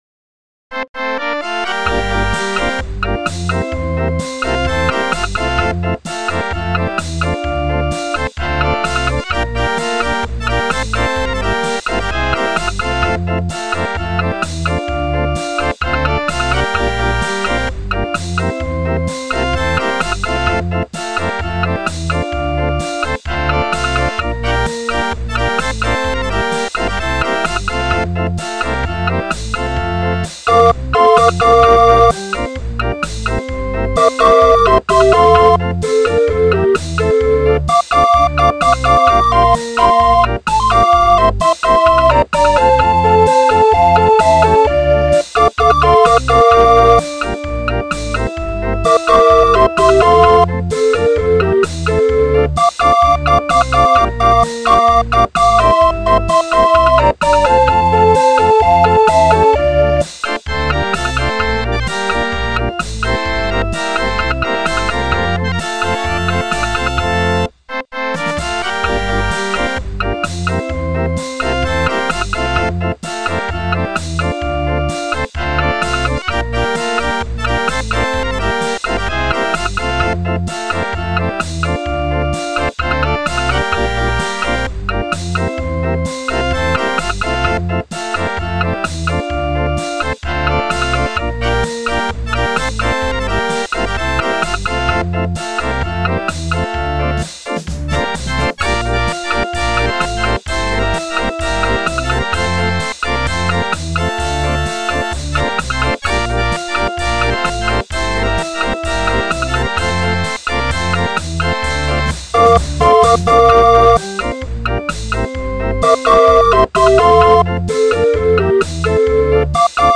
Music rolls, music books and accessoires for barrel organs.